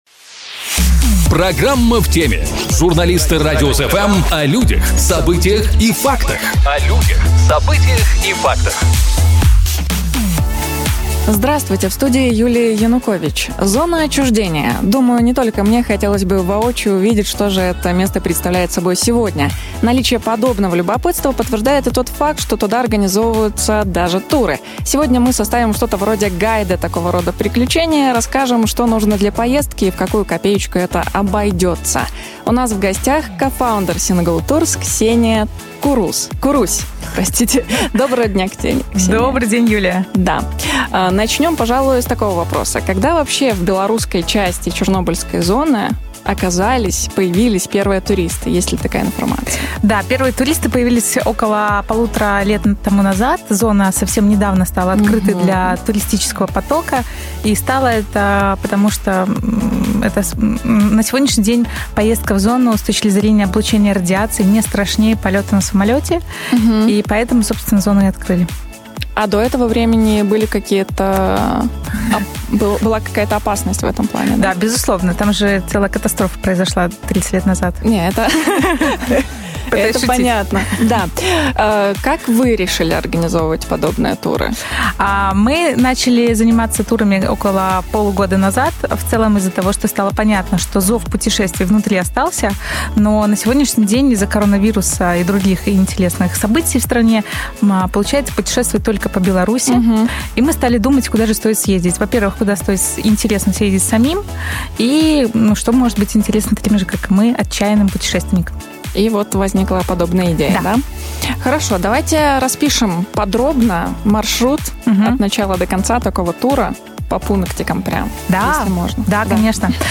Сегодня мы составим что-то вроде гайда такого рода тура, расскажем, что нужно для поездки и в какую копеечку это обойдётся. У нас в гостях